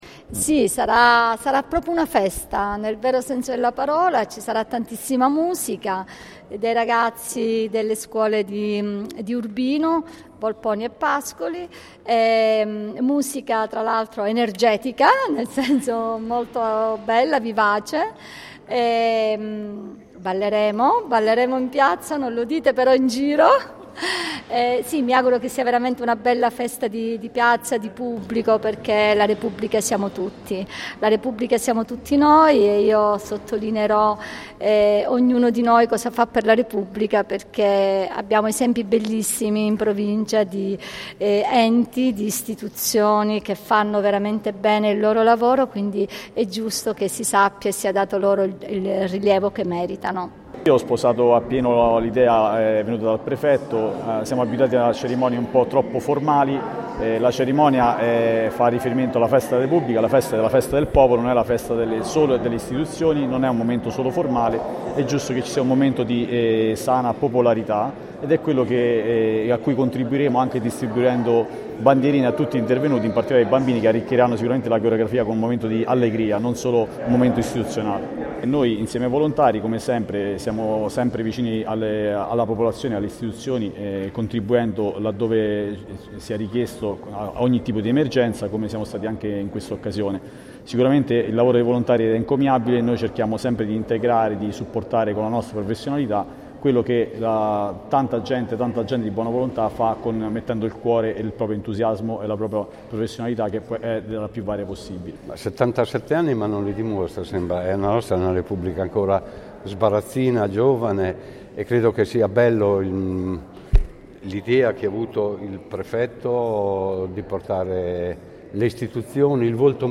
Ai nostri microfoni: Emanuela Saveria, Prefetto di Pesaro e Urbino, il Colonnello Antonio Di Leonardo, Comandante del 28° Reggimento Pavia, Giuseppe Paolini, Presidente della Provincia e Marco Perugini, Presidente del Consiglio Comunale di Pesaro.